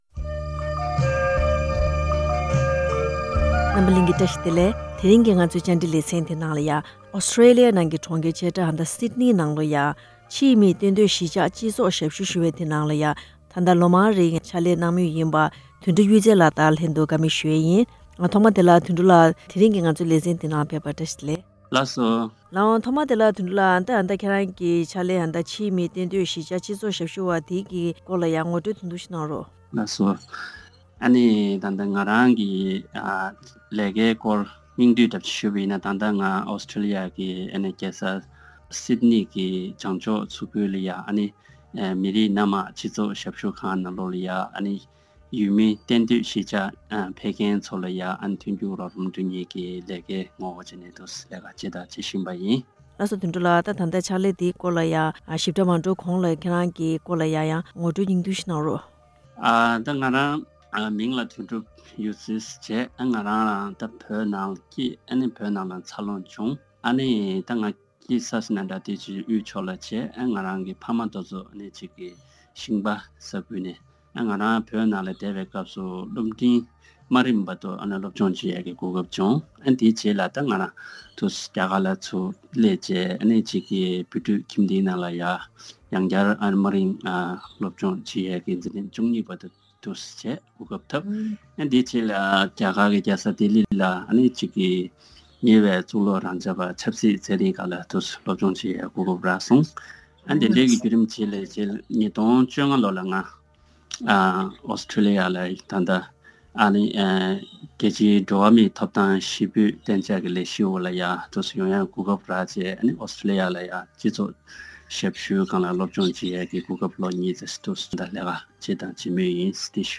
བཀའ་དྲི་ཞུས་པ་ཞིག